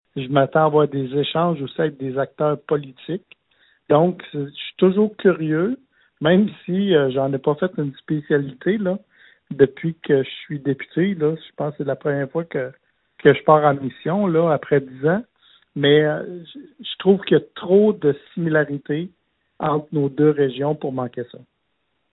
En entrevue, Donald Martel a laissé savoir qu’il a des attentes plutôt élevées de son voyage en France à Dunkerque, une ville qui a des similitudes avec Bécancour et la filière batterie.